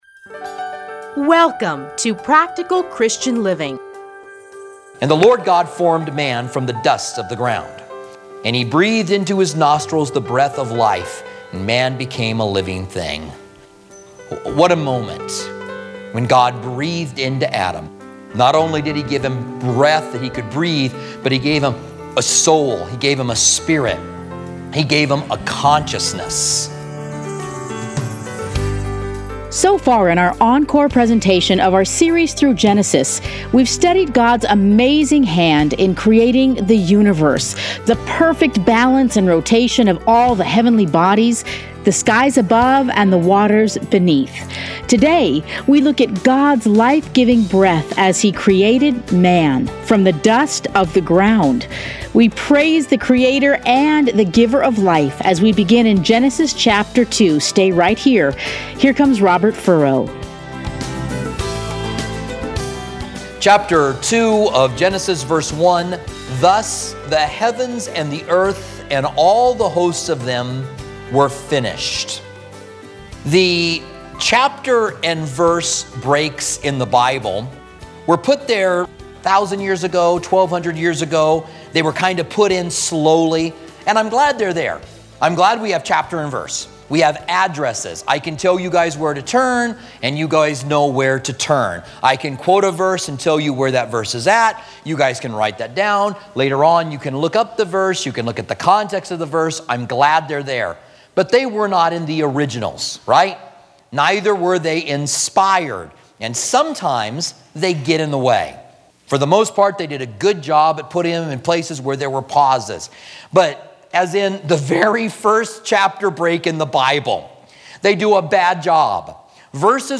Commentary Genesis 2 - Part 1
Listen here to a teaching from Genesis.